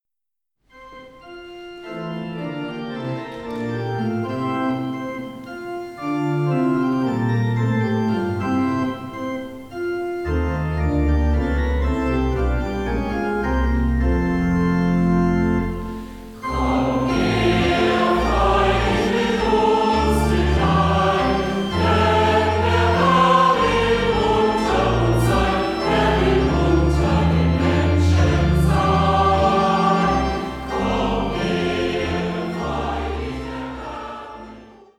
• kurzweilige Zusammenstellung verschiedener Live-Aufnahmen
Chor, Orgel